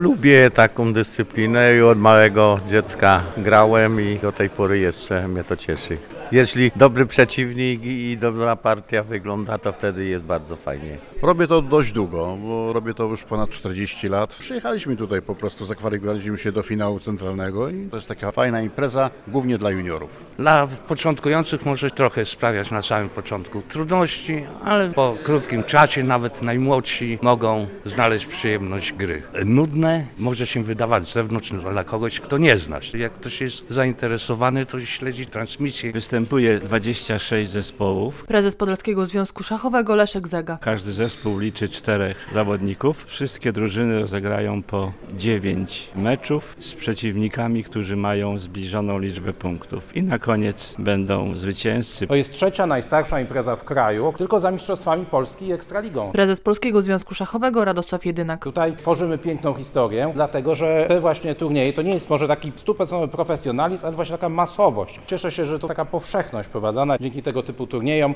130 zawodników z całej Polski walczy o "Złotą Wieżę" - relacja